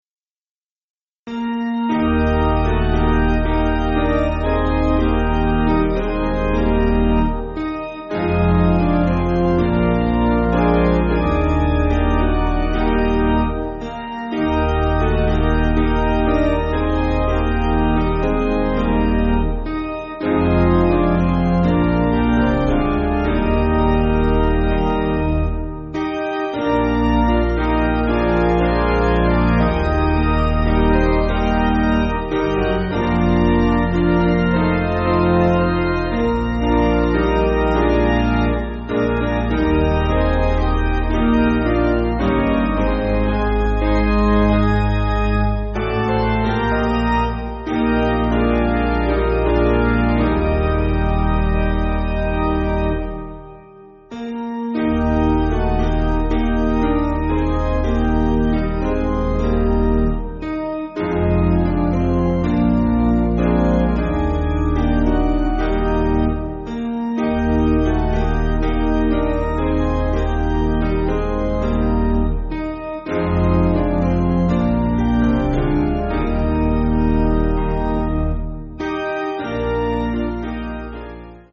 Basic Piano & Organ
(CM)   3/Eb